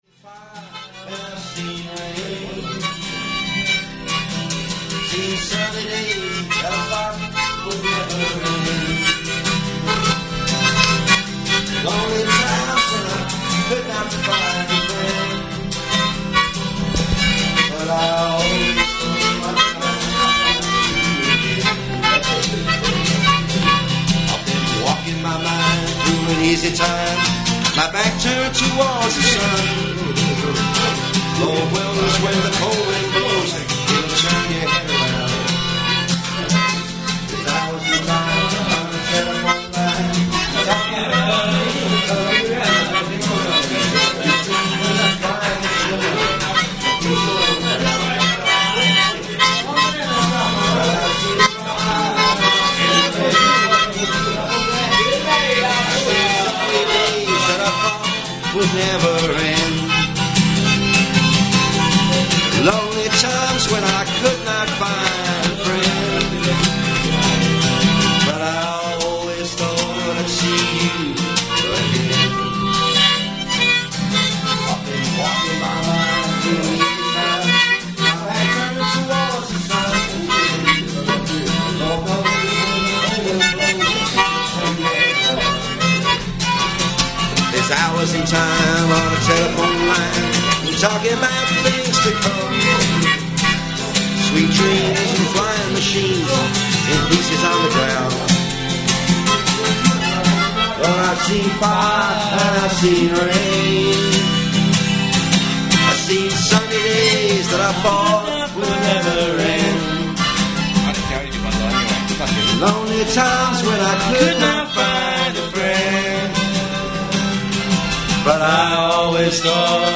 blues band